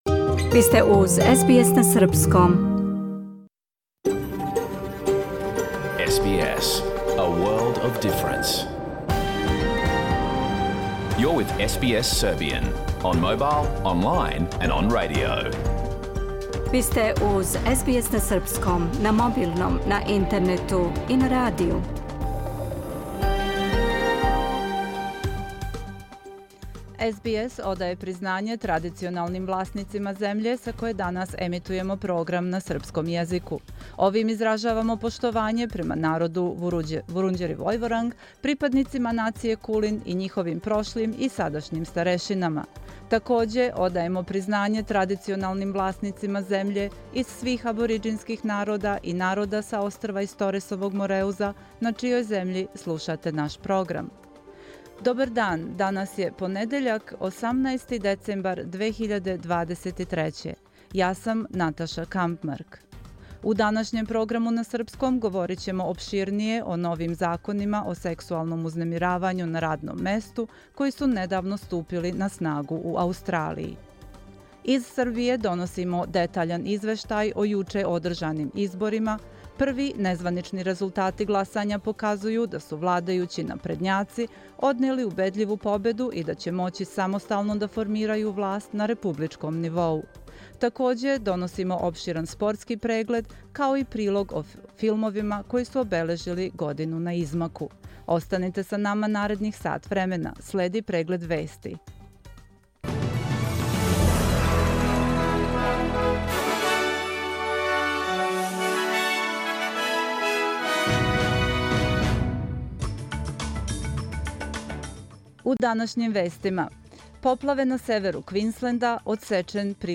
Програм емитован уживо 18. децембра 2023. године